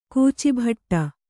♪ kūcibhaṭṭa